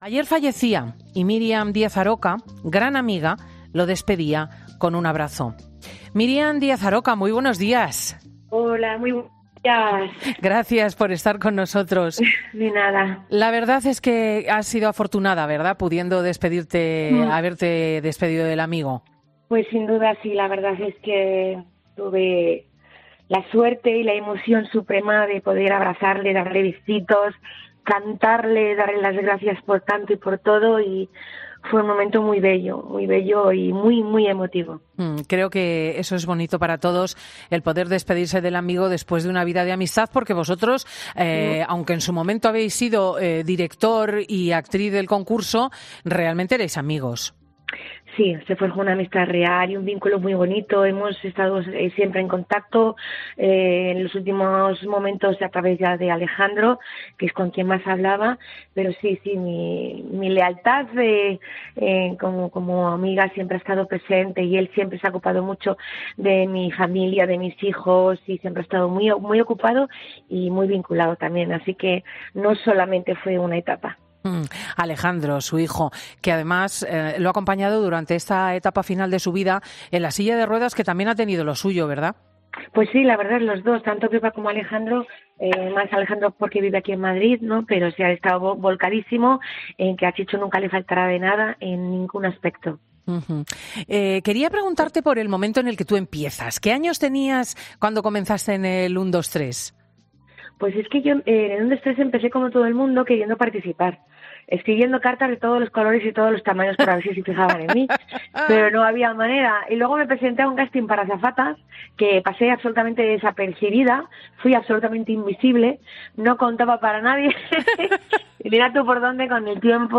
Cristina López Schlichting recuerda con Miriam Díaz Aroca la figura de Chicho Ibáñez Serrador, creador del mítico 'Un, dos tres'